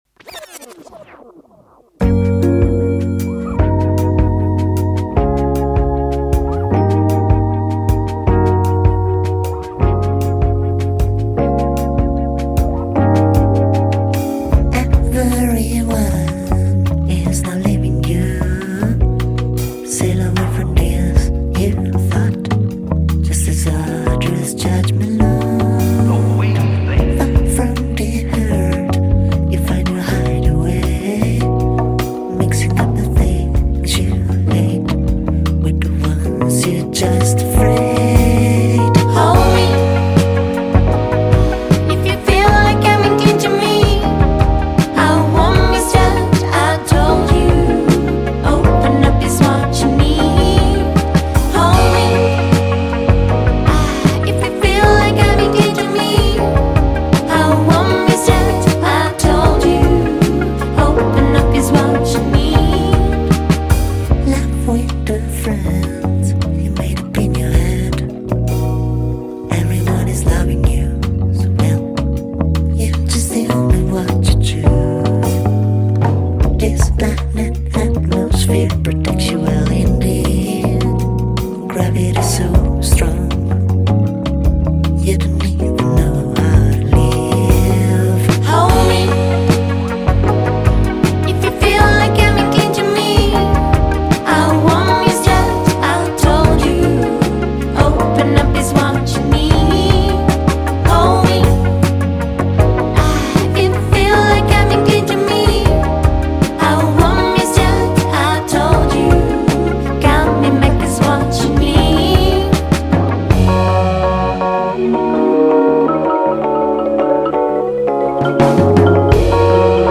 dúo psicodélico
El ambiente es muy relajado en general